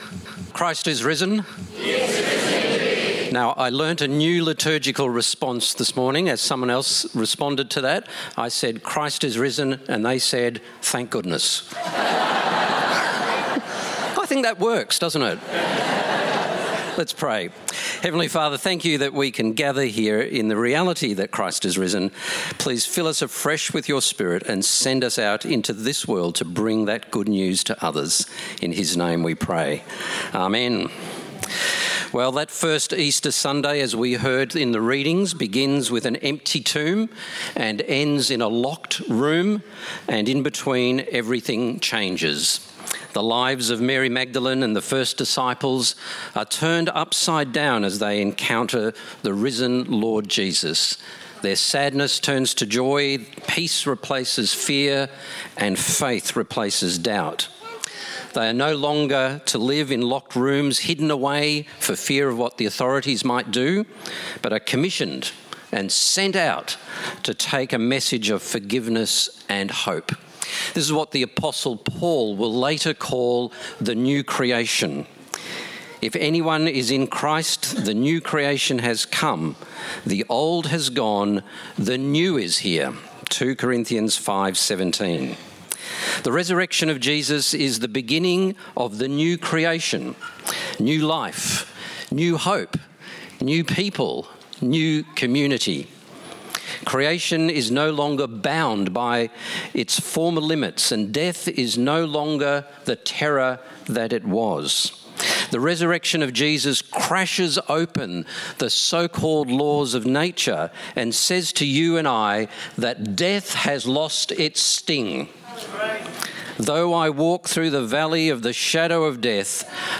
Download Download Bible Passage John 20:1–23 In this sermon